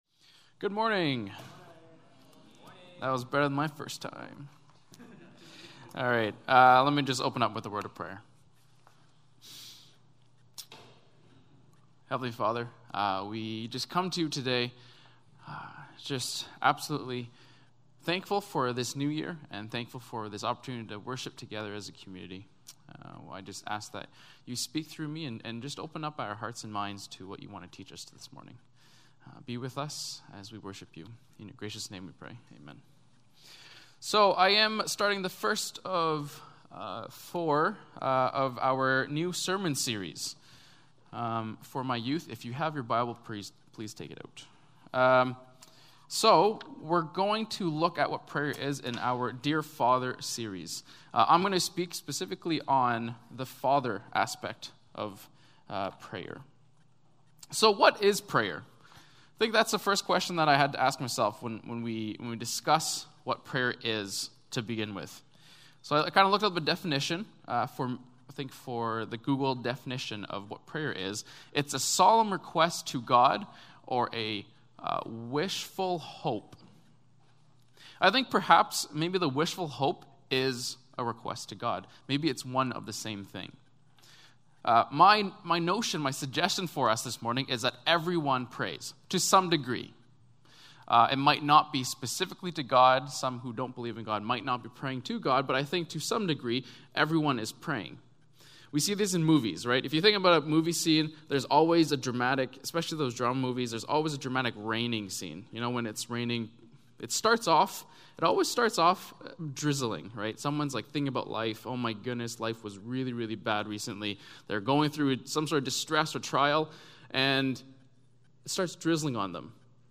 Sermons | Koinonia Evangelical Church | Live Different!